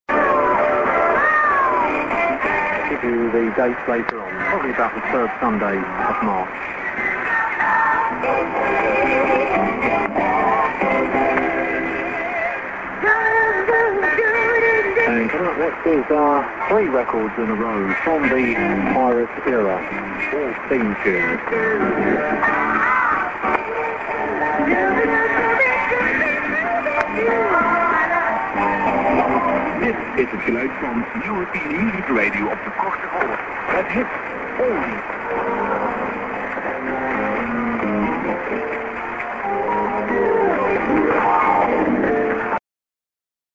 music->ID@00'30"->music 　＜私はこの時間で失礼しました。